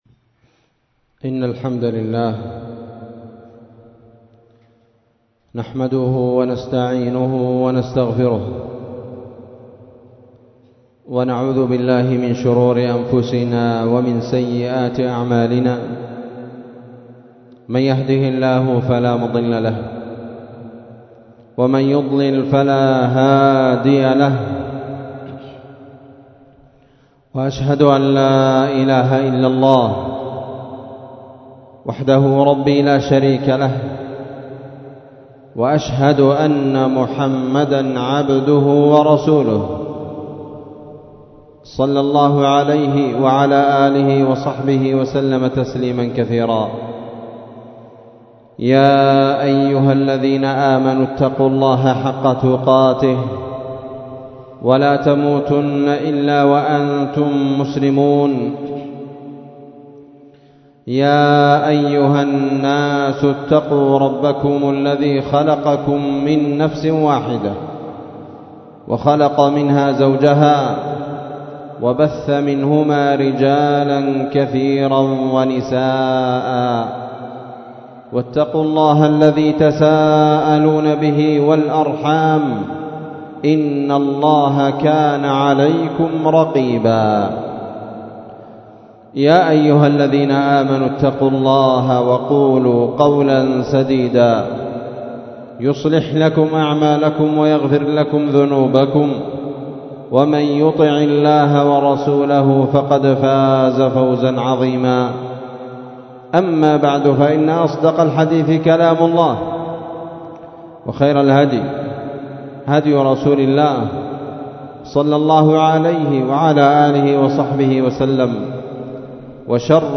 خطبة جمعة نسائمُ الأَرَج في أن سنة الله في خلقه إتباع الشدة الفرج بعنوان 16محرم1447ه